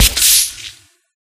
Splash.ogg